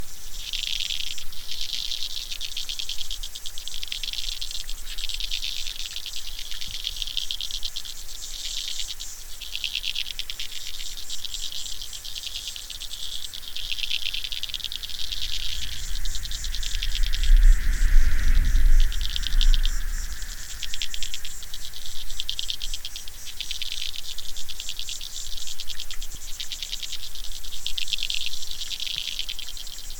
Below, a chorus of clicking macroinvertebrates fills the recording, until a vehicle sound cuts across from above the water's surface.
A waterbug chorus competes with the rumble of a passing vehicle.
waterbug-chorus-vehicle-noise-30sec.mp3